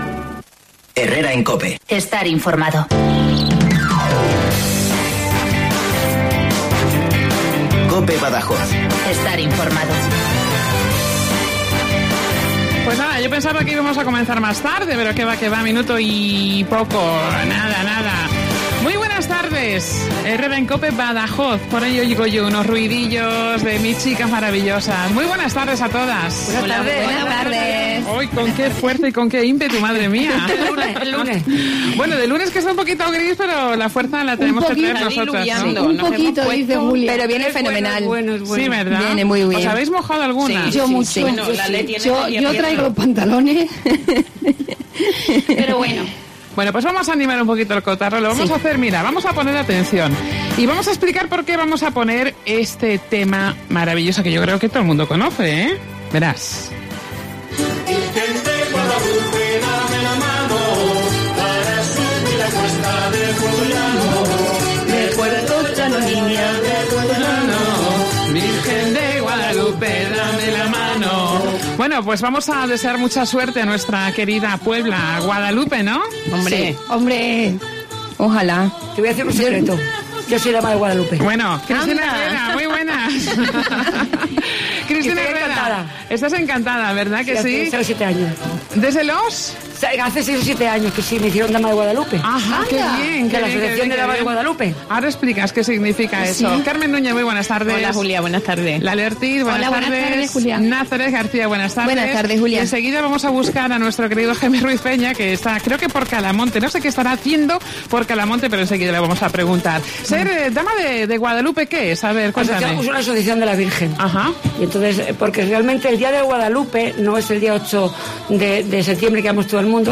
Hoy con la jota de Guadalupe animando el lunes gris y lluvioso que nos ha salido. Estamos todos pendientes de Guadalupe, donde probablemente este año podamos disfrutar de las campanadas de fin de año retransmitidas por Telecinco.